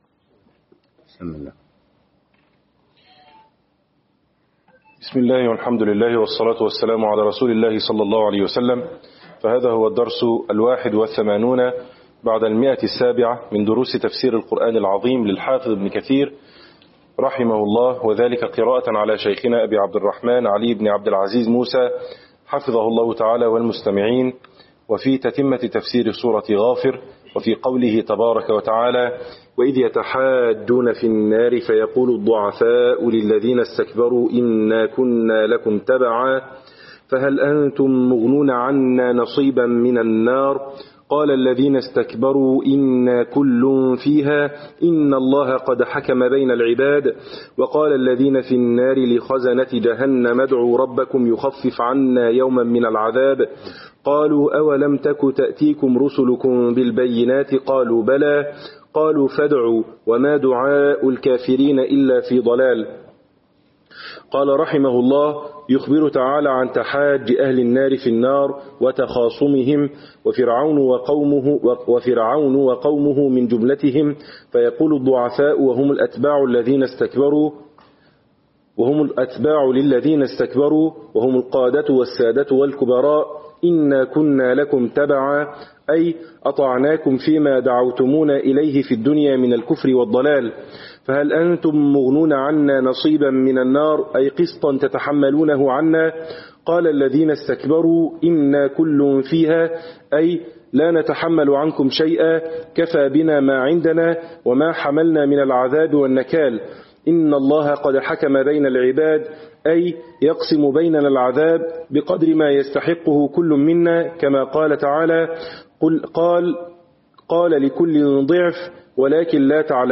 شرح تفسير ابن كثير الدرس 781